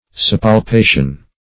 Search Result for " suppalpation" : The Collaborative International Dictionary of English v.0.48: Suppalpation \Sup`pal*pa"tion\, n. [L. suppalpari to caress a little; sub under, a little + palpare to caress.] The act of enticing by soft words; enticement.